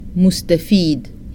Sudanese Arabic Vocabulary List